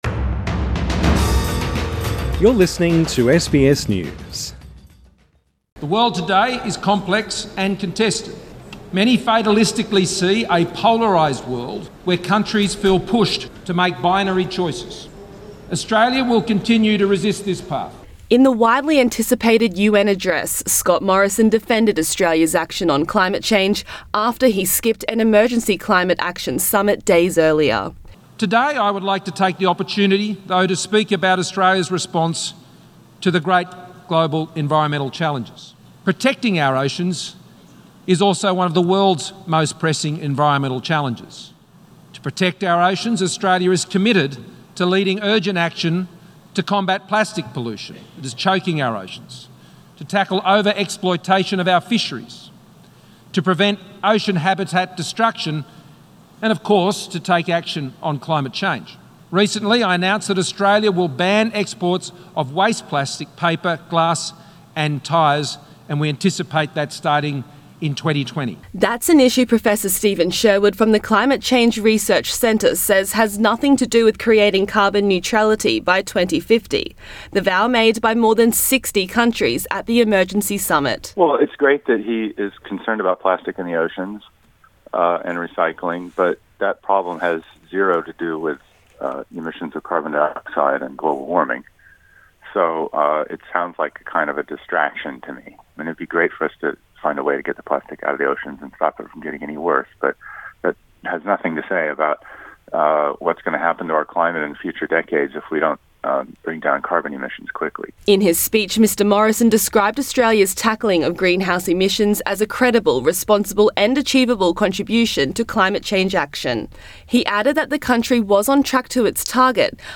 With the eyes of the world watching, Prime Minister Scott Morrison has hit out at the critics of his government’s climate change policies in his address to the United Nations in New York.
Scott Morrison addresses the United Nations in New York Source: AAP